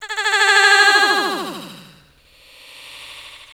H378VOCAL.wav